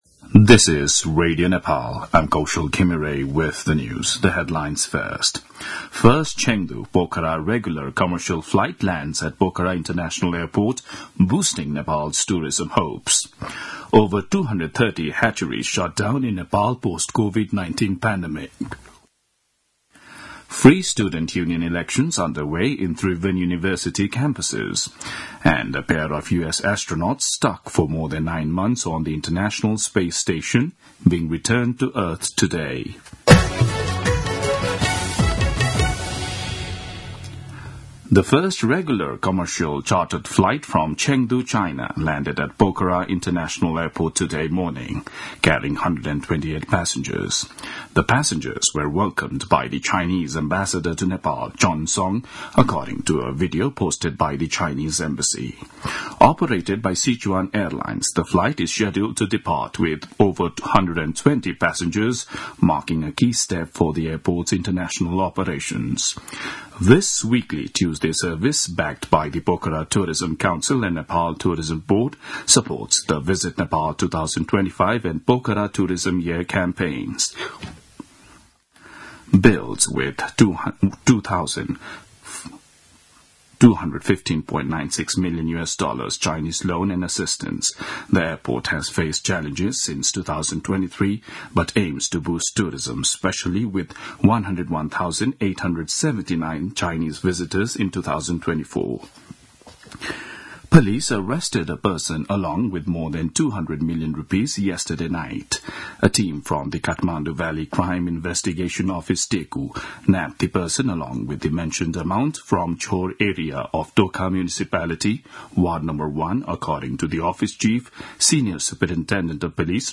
दिउँसो २ बजेको अङ्ग्रेजी समाचार : ५ चैत , २०८१
2-pm-news-1-4.mp3